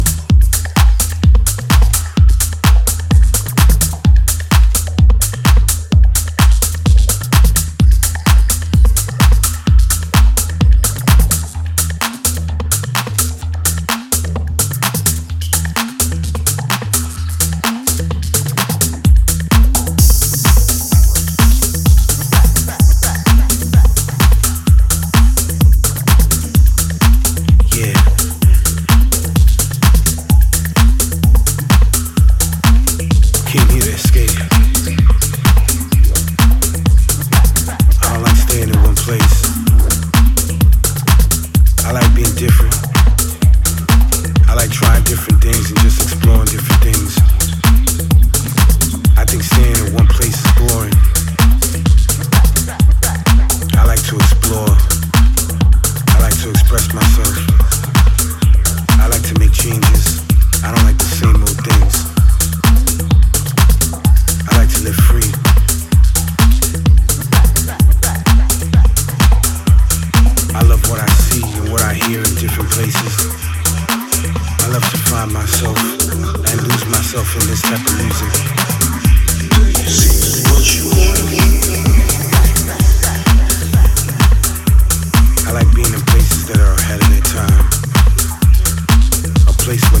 a mesmerizing and slice of anthemic vocal house